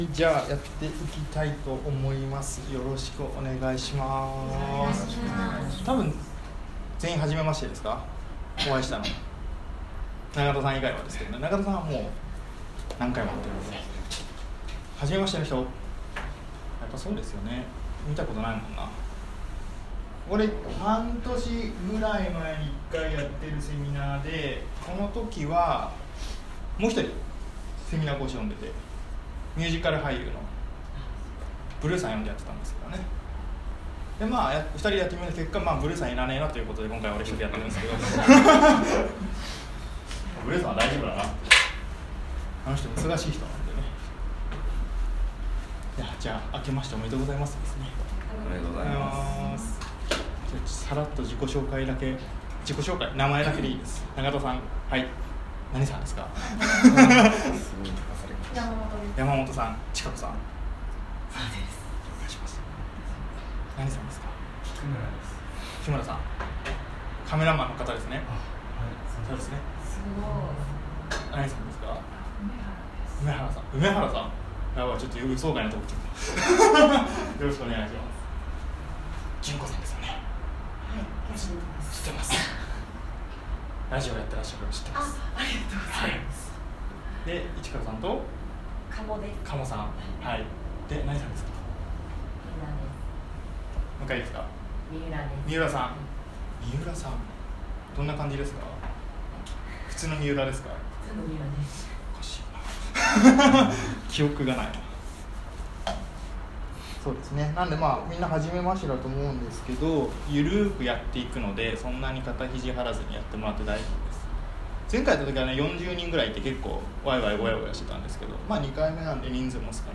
強みや個性の見つけ方、作り方セミナー
強み・個性の覚醒セミナー２０１８Part.1.m4a